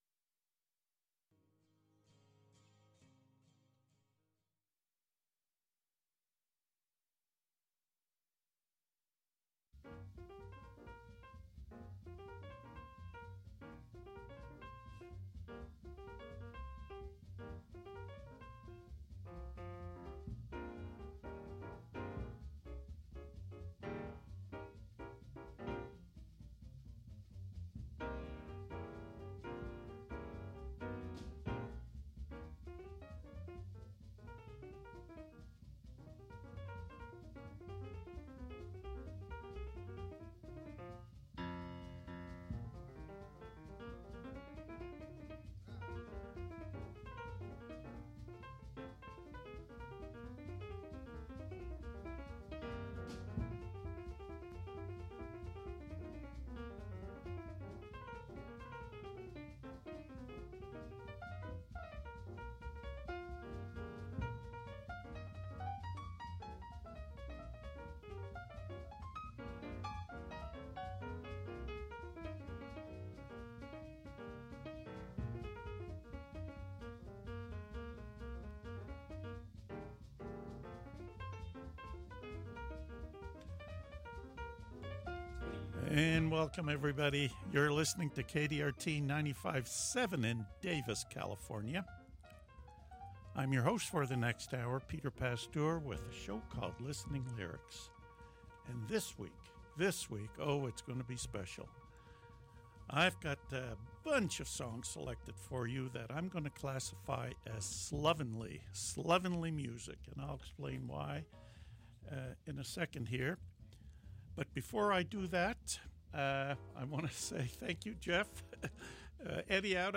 Right here on KDRT 95.7FM in Davis, California. Listening Lyrics is a genre free zone - we feature the artist.
Listen to the hope and satisfaction in their voices.